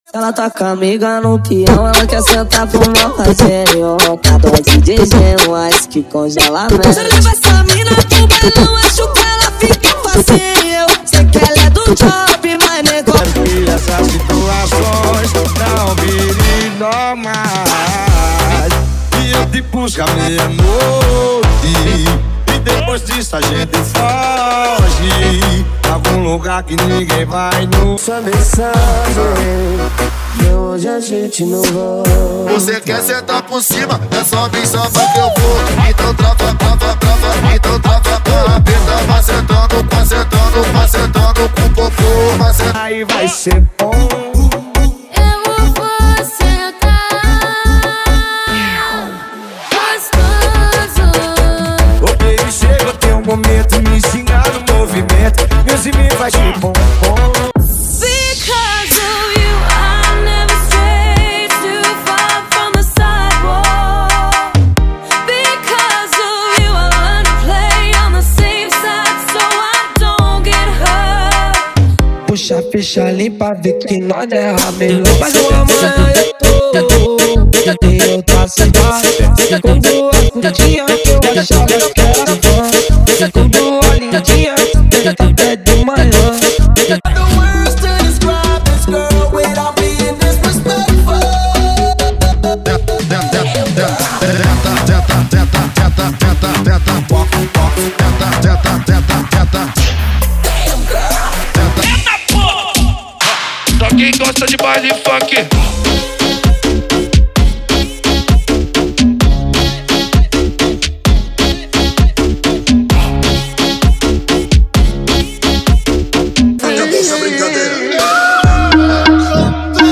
• Funk Light e Funk Remix = 100 Músicas
• Sem Vinhetas
• Em Alta Qualidade